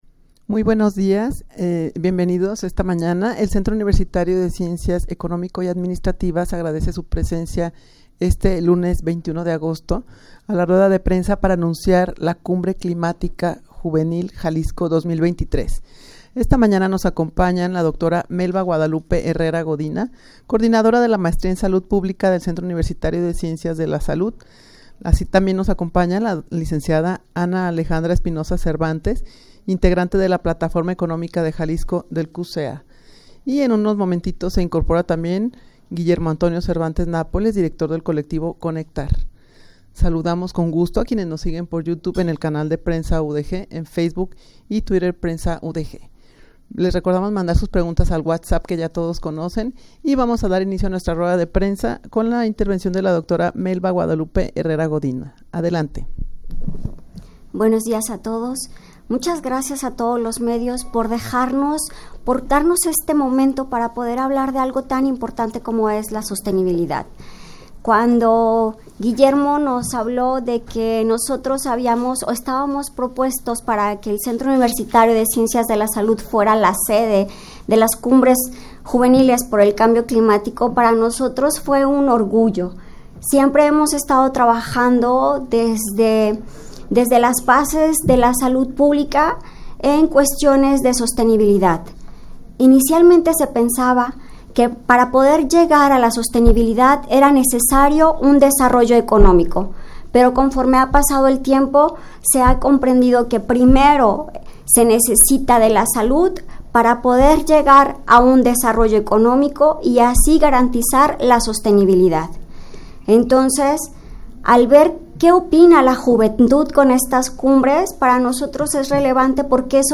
Audio de la Rueda de Prensa
rueda-de-prensa-para-anunciar-la-cumbre-climatica-juvenil-jalisco-2023.mp3